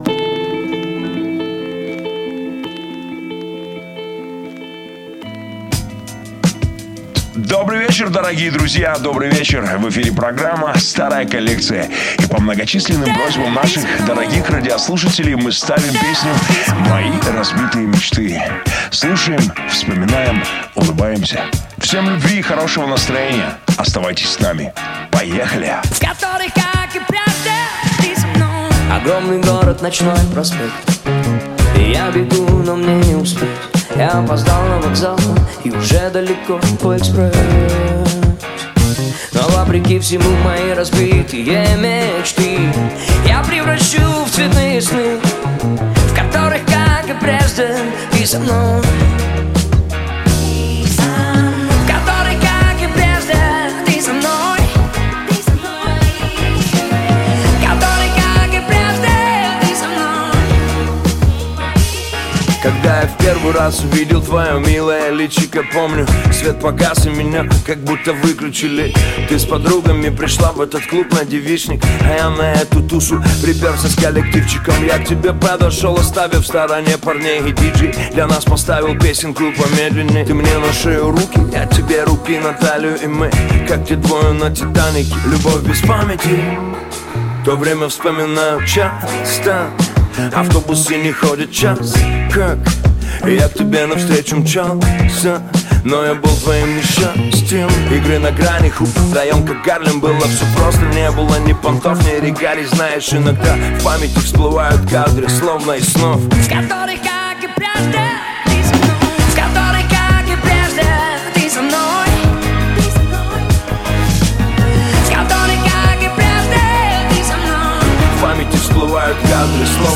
• Жанр песни: Русский рэп / Хип-хоп